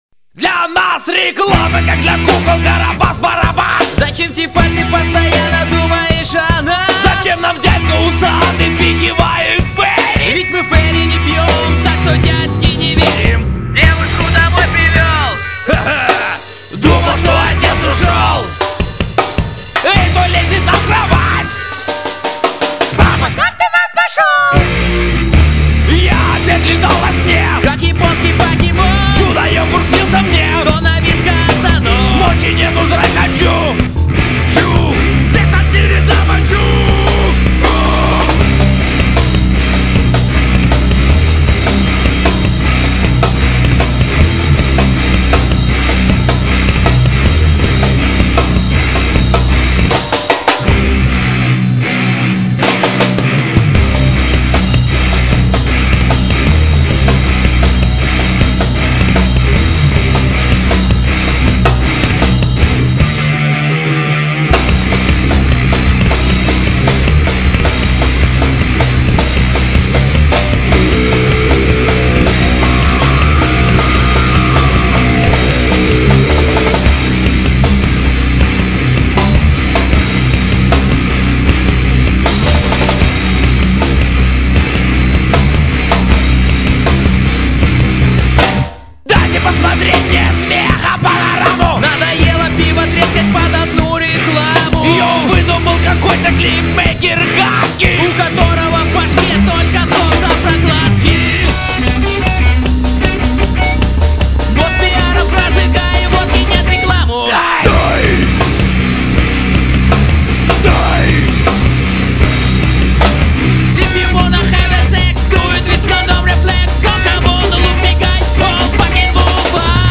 Слушай и ОХУЕВАЙ уважаемый товаРищ.. вот Где калужСкий ТРЕШ-МЕТАЛЛ.... Качесво естессна УРЕЗал.. а то НЕ вЛеЗЕть!